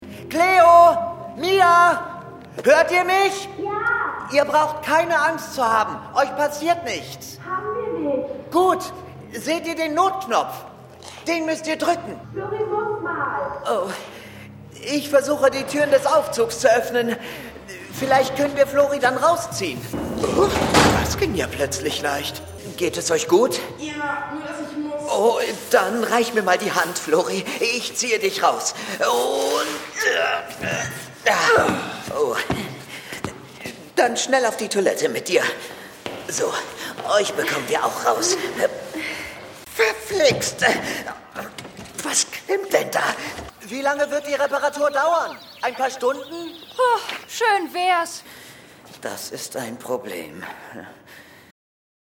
Off, Presentation, Commercial (Werbung)